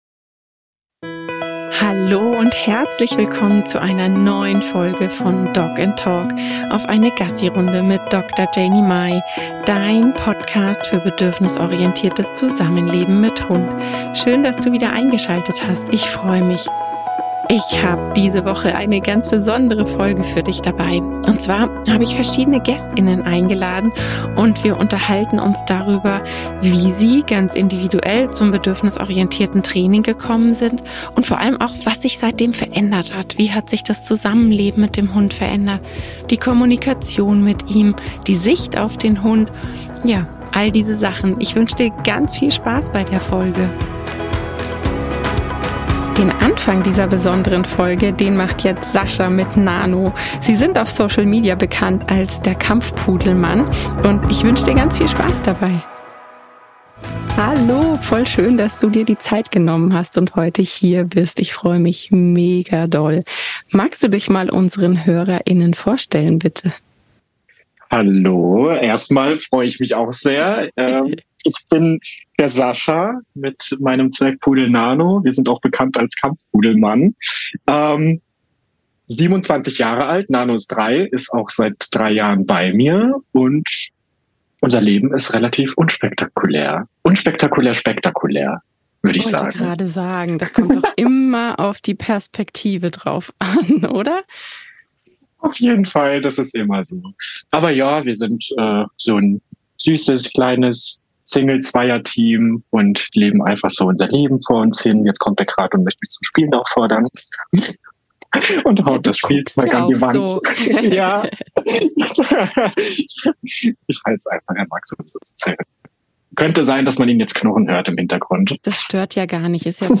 In dieser Folge kommt meine Community zu Wort. Verschiedene Wege ins und Gedanken zum bedürfnisorientierten Zusammenleben erwarten dich.